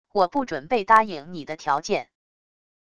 我不准备答应你的条件wav音频生成系统WAV Audio Player